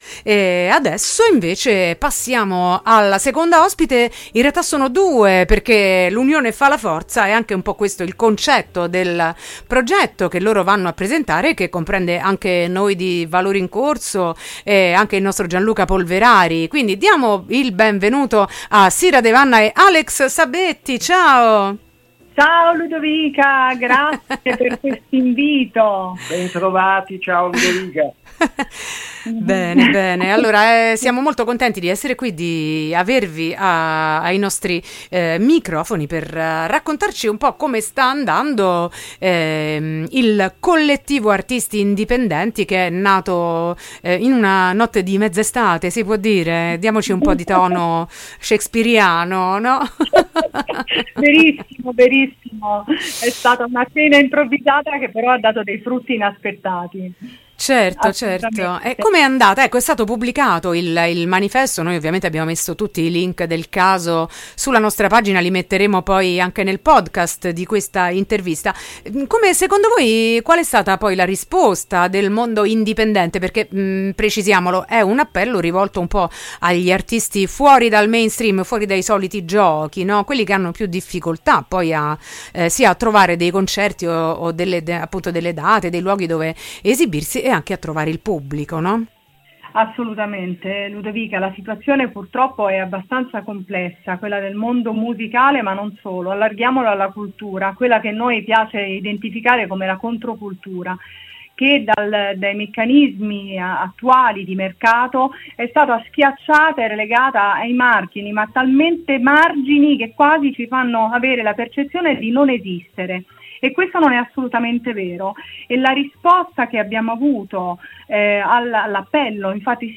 Nel corso dell’intervista abbiamo ascoltato i brani di alcuni degli artisti che hanno aderito al Kollettivo.